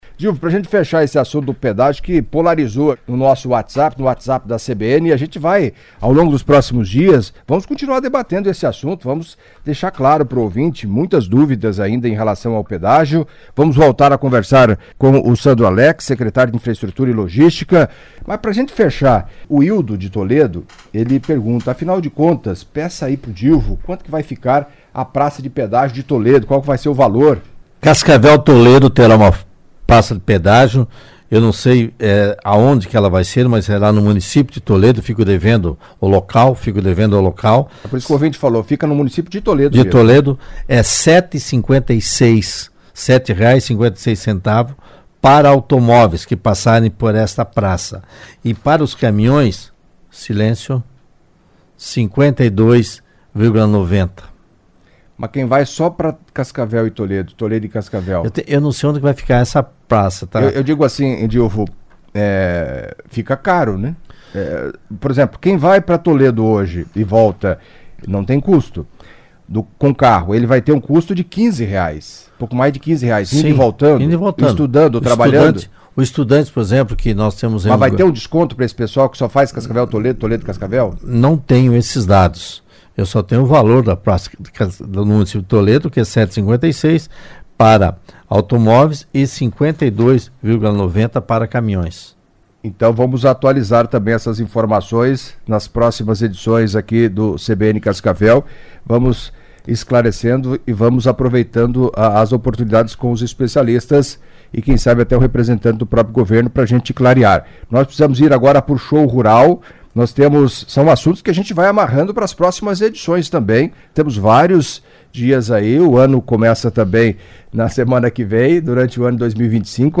Editoriais
Entrevista